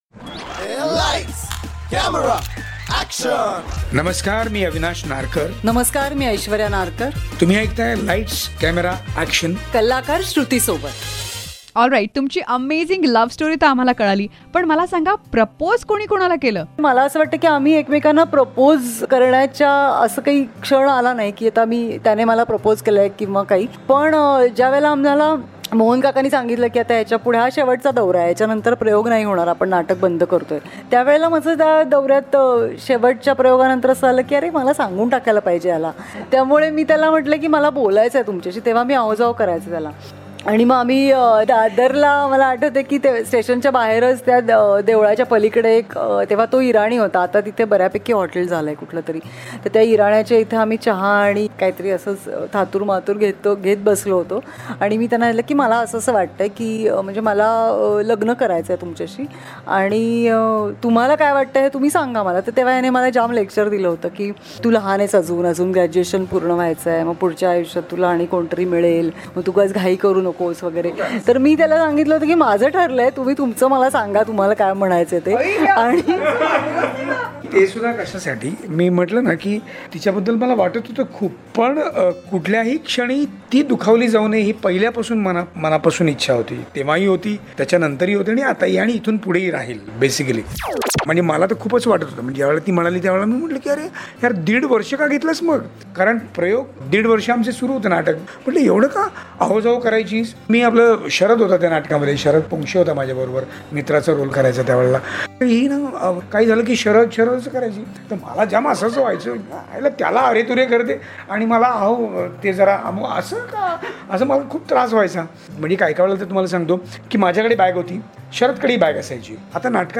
Listen to this podcast as the cutest romantic couple talks about their journey of life exclusively on Lights Camera Action.